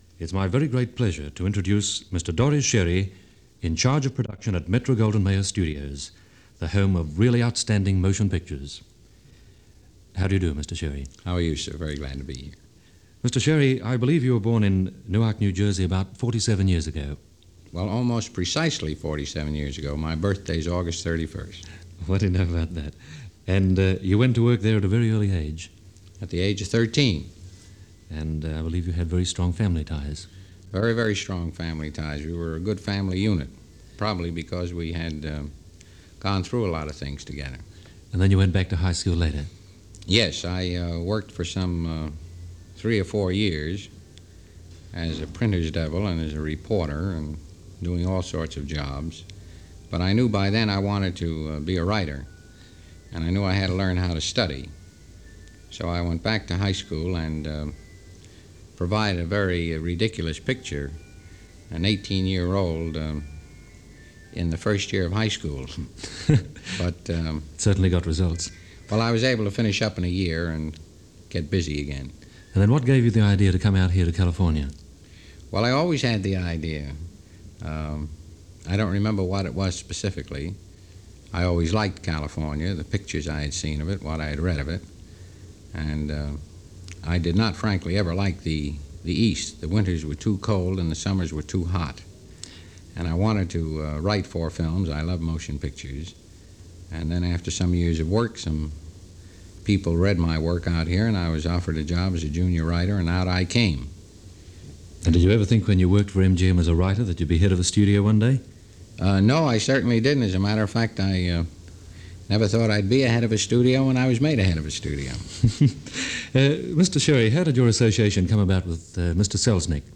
Dore Schary In Conversation - Recorded For Radio Australia, September 26, 1952 - Past Daily Goes To The Movies: The 1950s.
Dore-Schary-Interview-1952.mp3